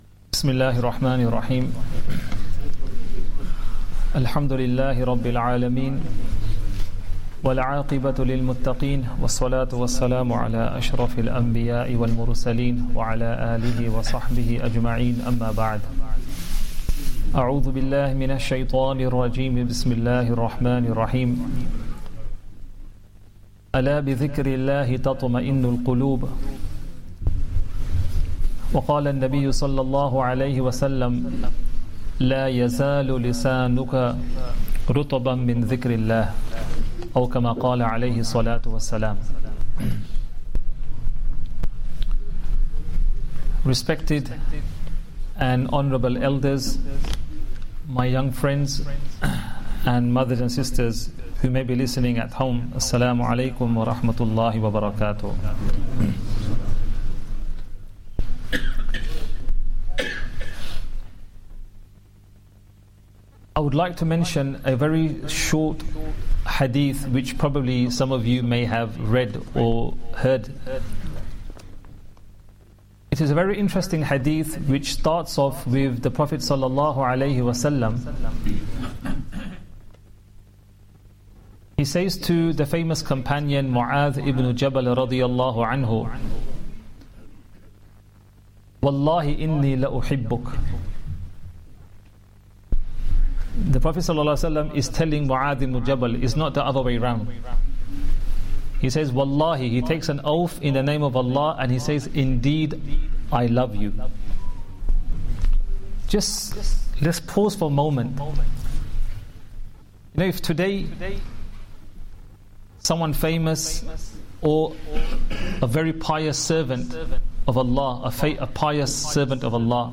Ramadhan Day 9 Asr Talk